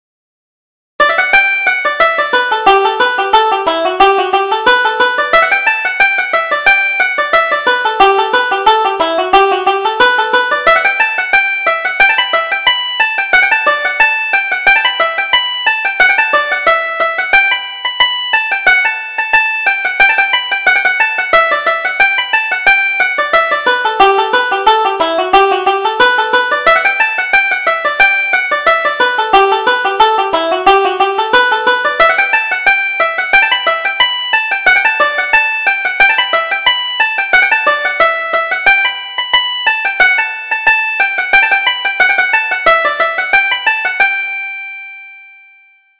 Reel: Winter Apples